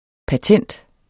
Udtale [ paˈtεnˀd ]